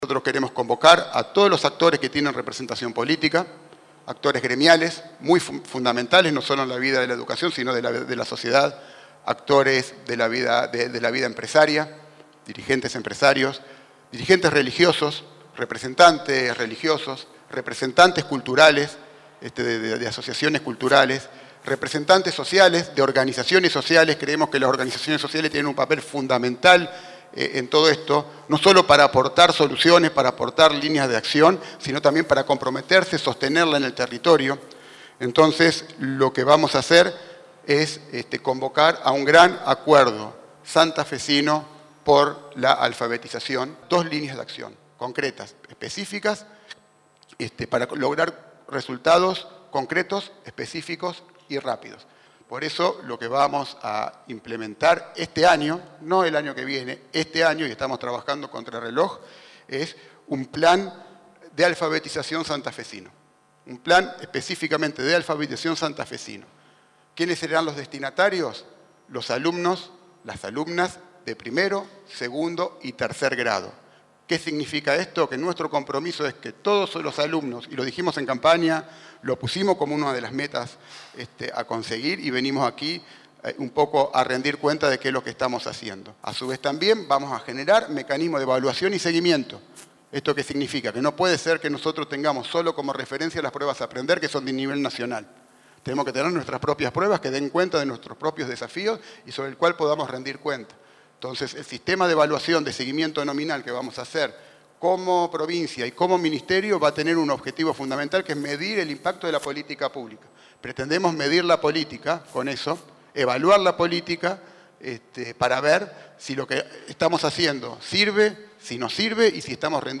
El ministro de Educación, José Goity, y el secretario General de la Gobernación, Juan Cruz Cándido, encabezaron la conferencia de prensa.